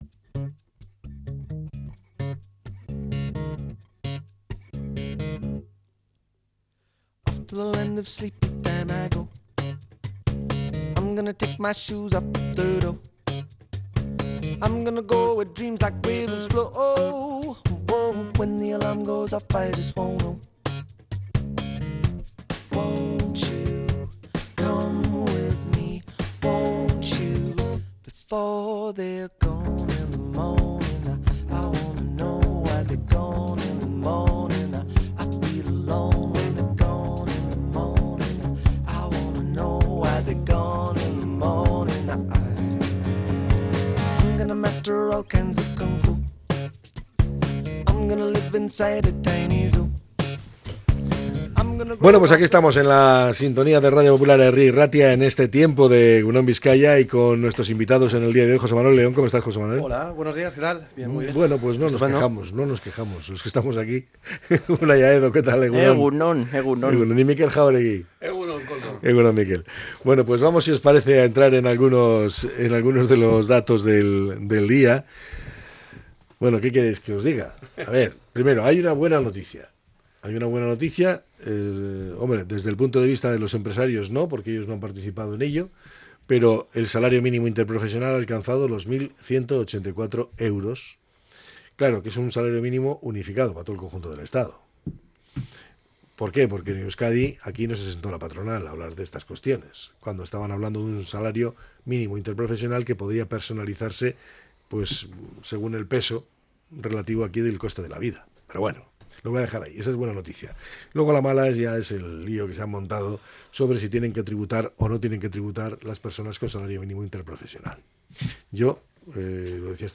La tertulia 12-02-25.